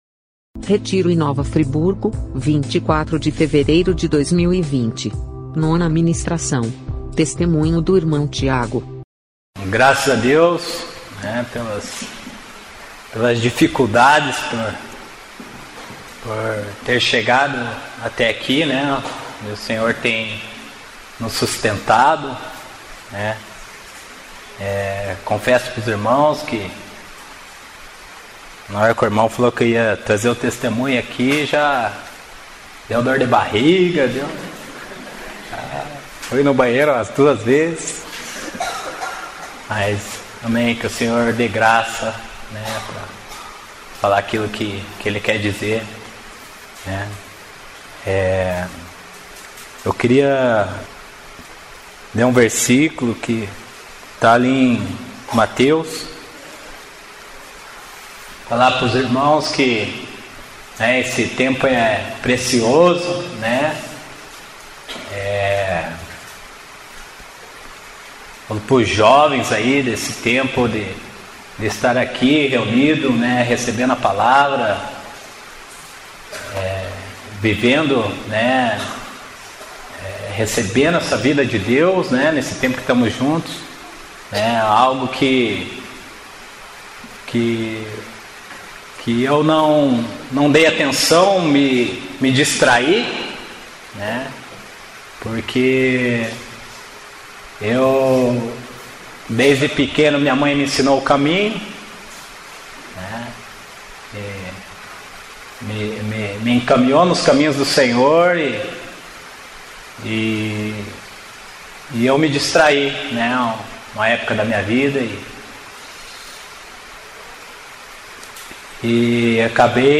Mensagens compartilhadas no retiro em Nova Friburgo entre os dias 22 e 25/02/2020.